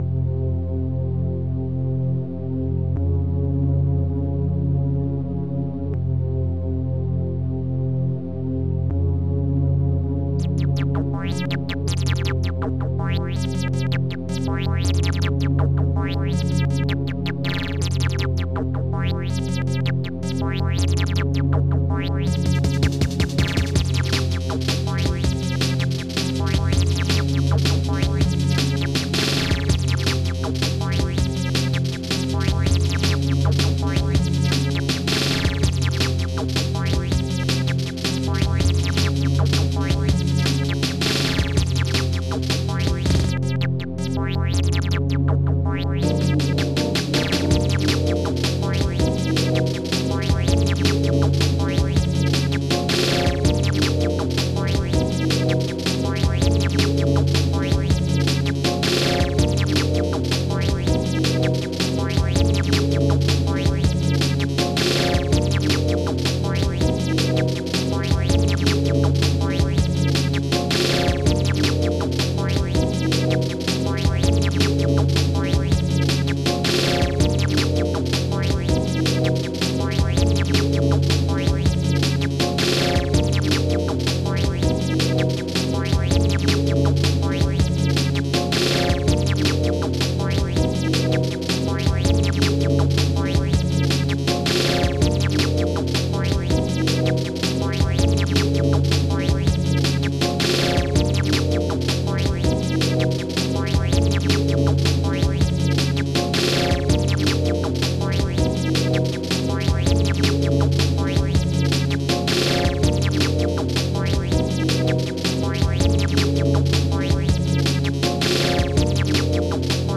OctaMED Module
Type MED/OctaMED (4ch)